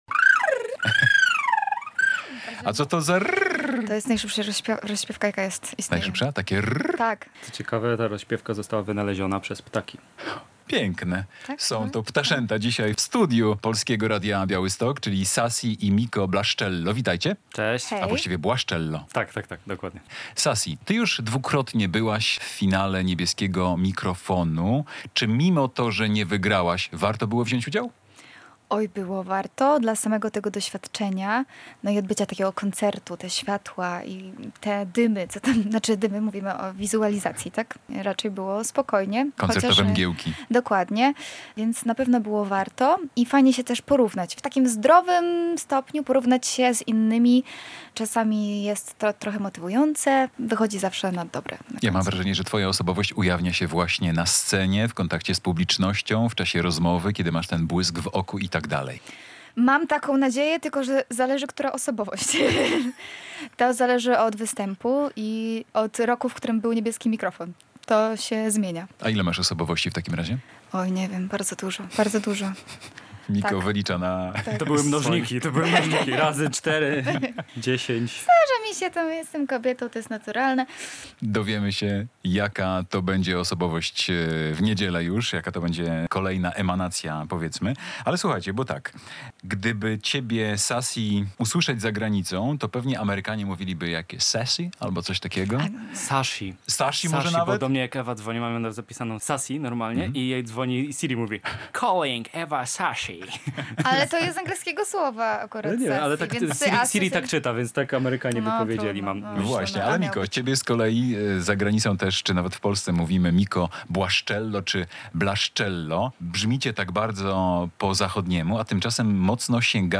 muzycy